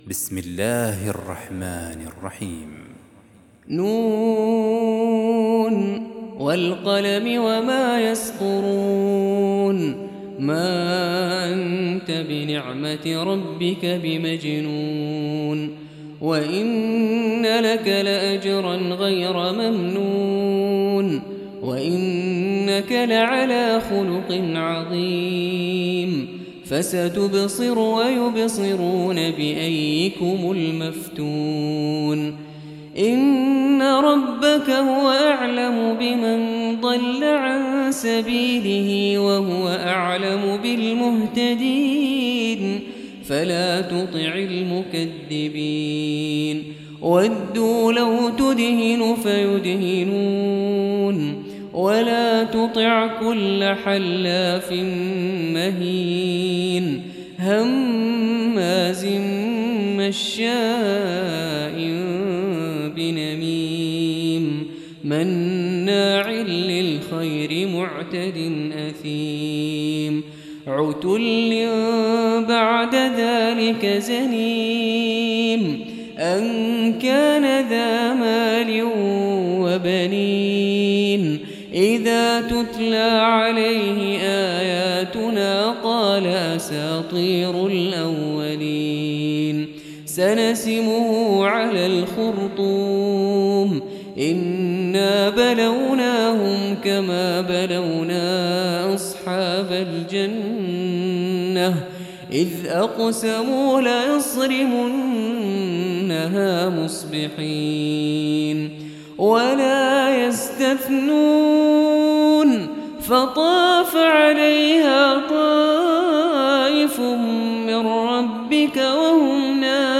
68. Surah Al-Qalam سورة القلم Audio Quran Tarteel Recitation
Surah Repeating تكرار السورة Download Surah حمّل السورة Reciting Murattalah Audio for 68.